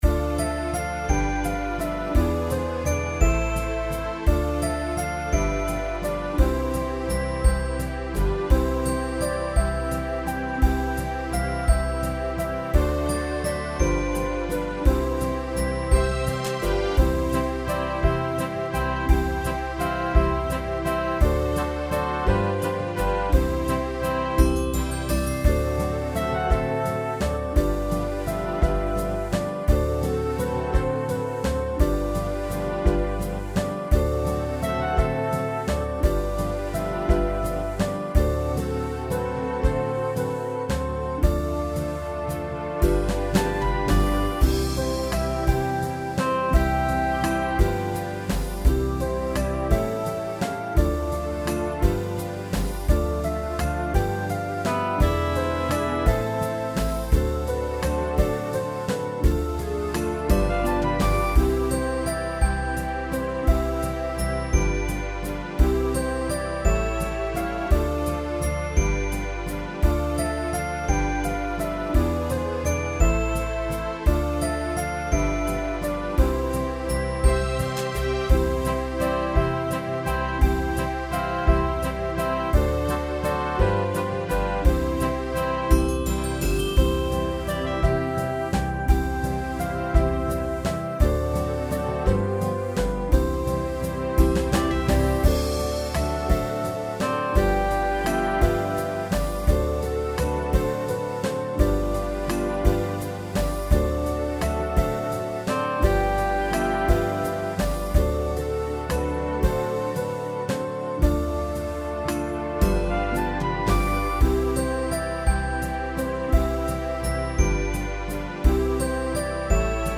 軽快なワルツに乗って、橋の下での楽しい生活が描かれた歌です。
♪演奏サンプル（自動生成）